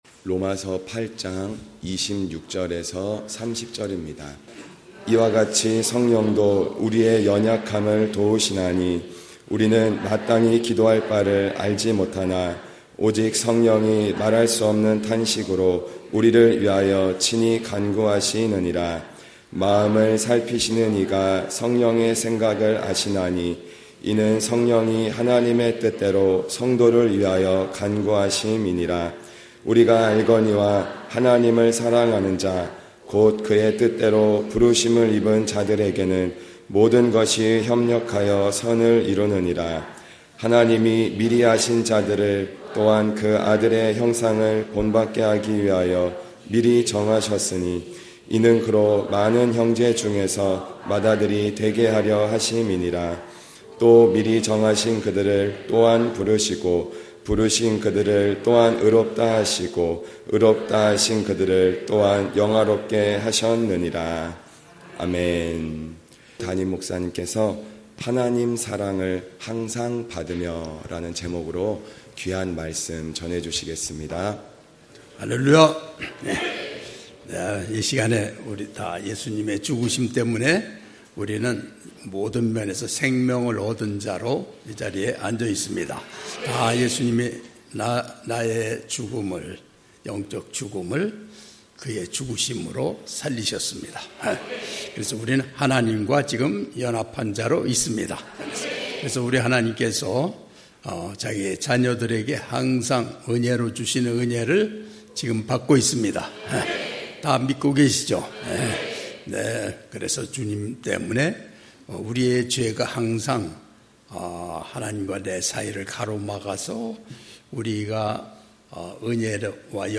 주일9시예배